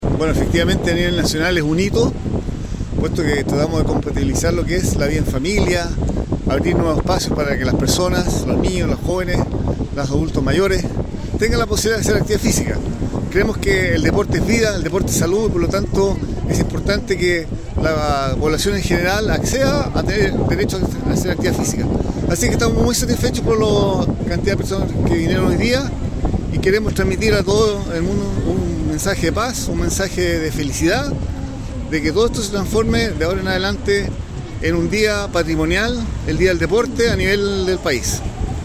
Por su parte el Seremi del Deporte, Fernando Medina Vergara, destacó que es un hito a nivel nacional como una gran actividad,
DIA-DEL-DEPORTE-Fernando-Medina-Seremi-del-Deporte.mp3